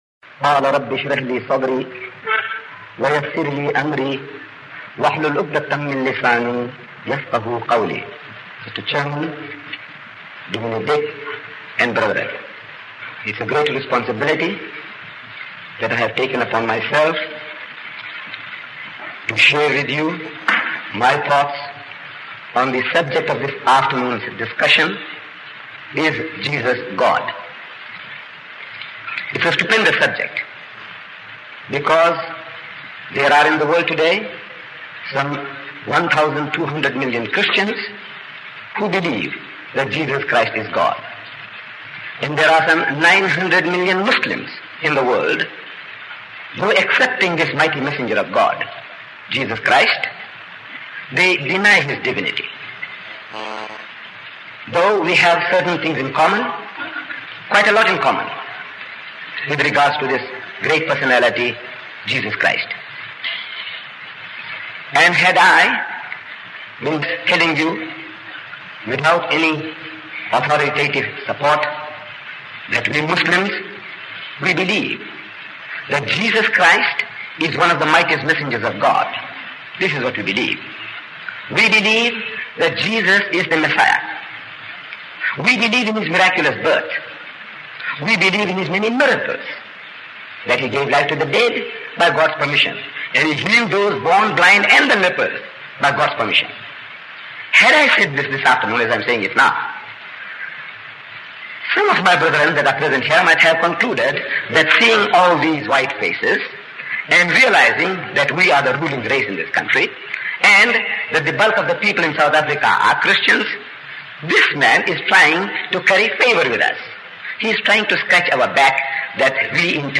These lectures were delivered by some scholars in which they discussed many topics about Islam such as the two testimonies, how to pray, how to be happy, some topics about women and the issue of divinity of the prophet Jesus (peace be upon him).